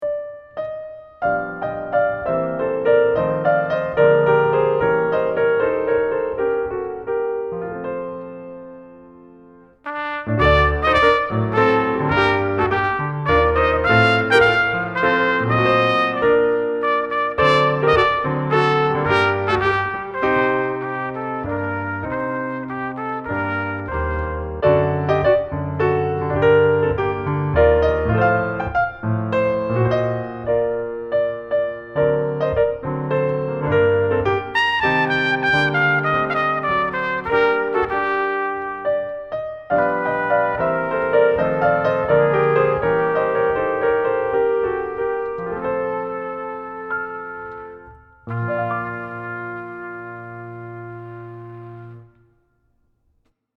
flute -
trumpet -